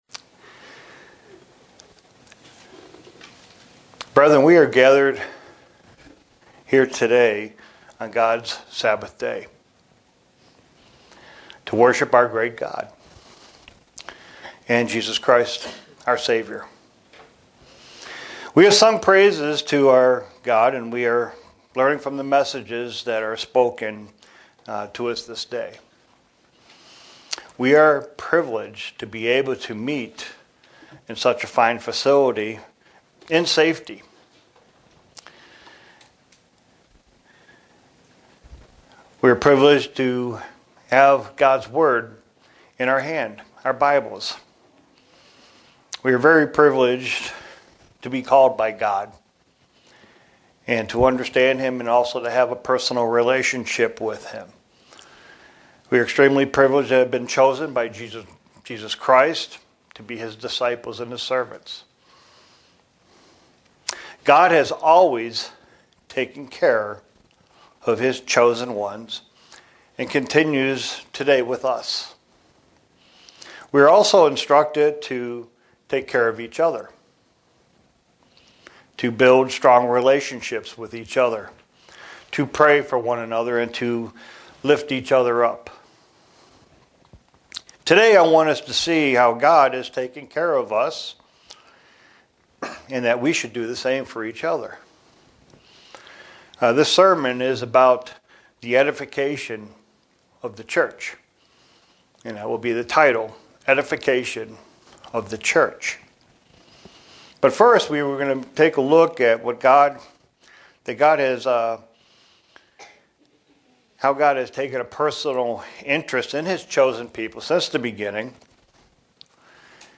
Print To see how God is taking care us in the Church and how we should take care of each other. sermon Studying the bible?